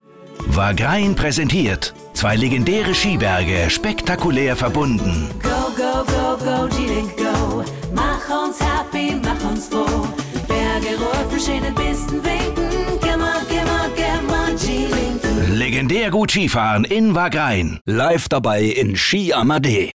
Hörfunk
Unter anderem mit diesem eigens komponierten Jingle